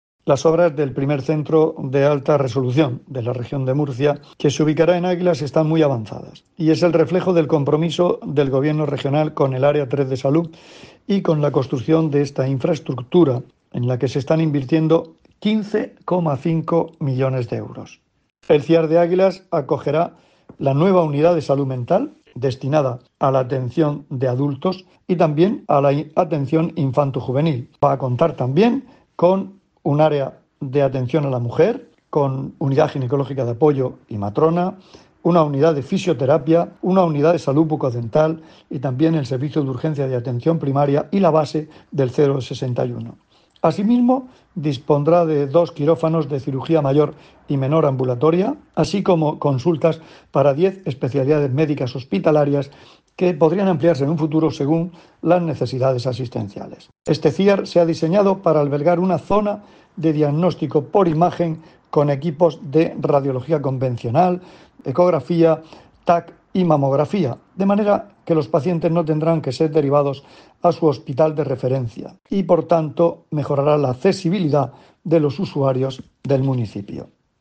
Sonido/ Declaraciones del consejero de Salud, Juan José Pedreño, sobre el primer Centro de Alta Resolución de la Región que se ubicará en Águilas.